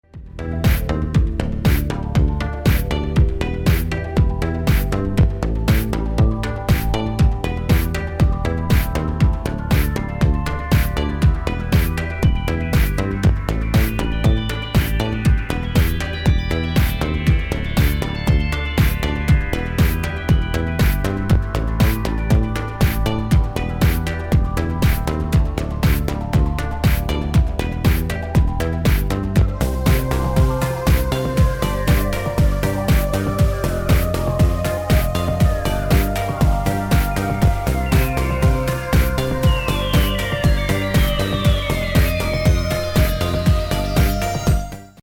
to hazy hymns/understated pop tunes